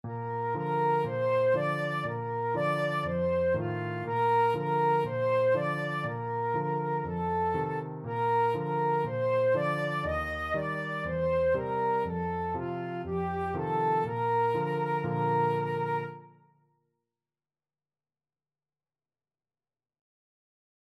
Flute
4/4 (View more 4/4 Music)
Bb major (Sounding Pitch) (View more Bb major Music for Flute )
Traditional (View more Traditional Flute Music)